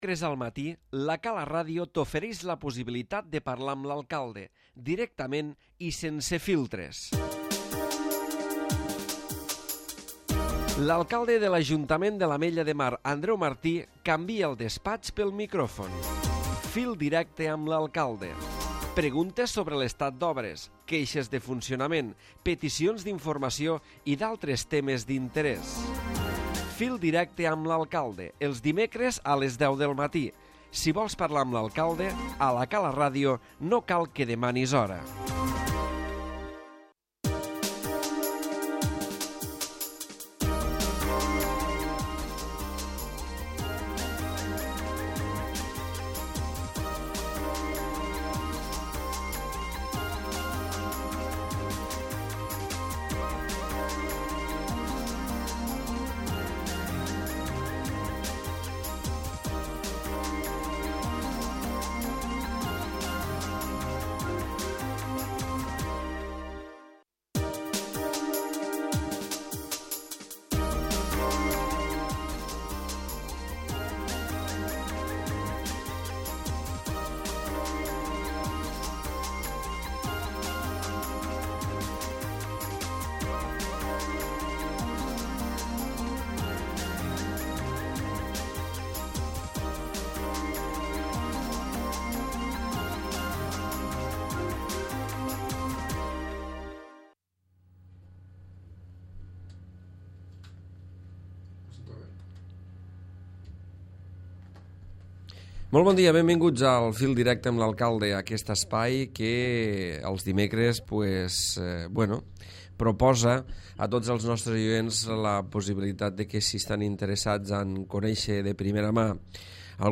Després del parentesi nadalenc s'ha encetat novament aquest espai on els ciutadans poden trucar per preguntar, suggerir o queixar-se d'alguns temes d'ambit municipal, amb la presència de l'Alcalde Andreu Martí.